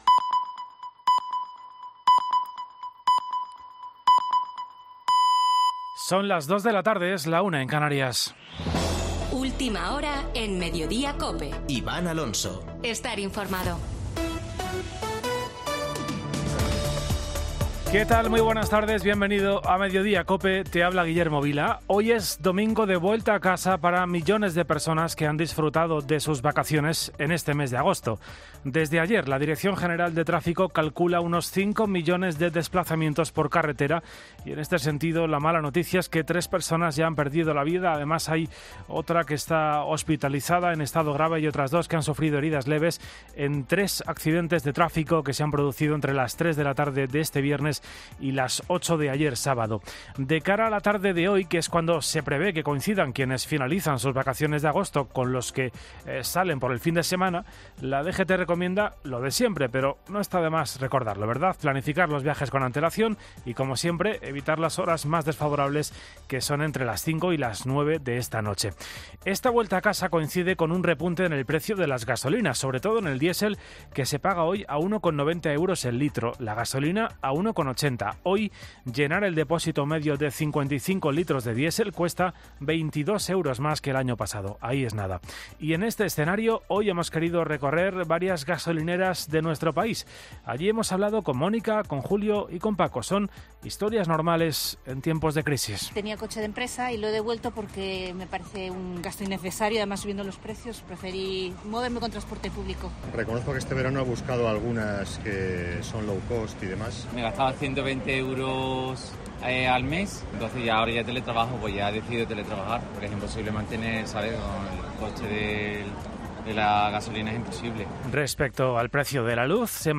Boletín de noticias de COPE del 28 de agosto de 2022 a las 14.00 horas